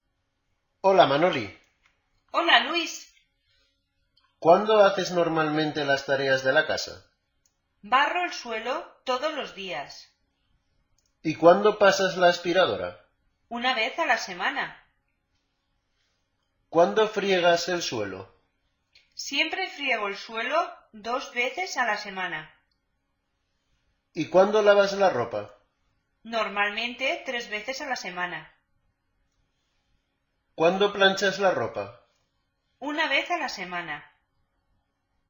Escucha la siguiente conversación y selecciona la frecuencia con que Manoli hace las tareas de la casa.